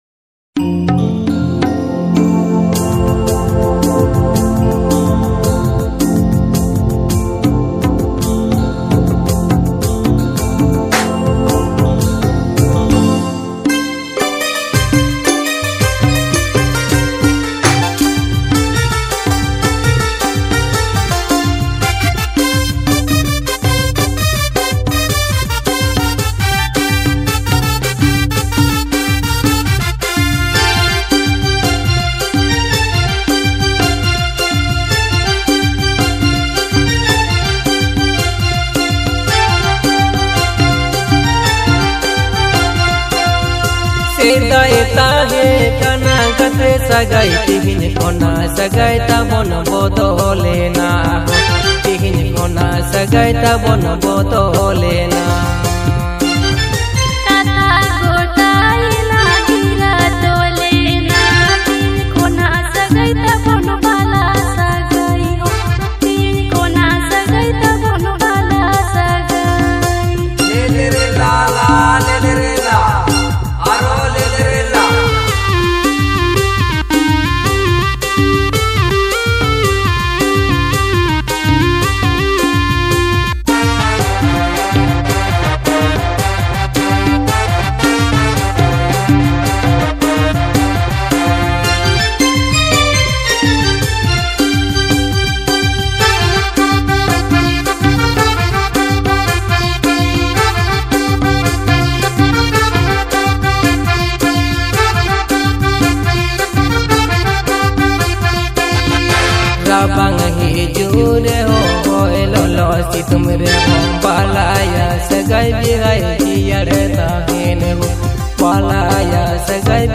Santali song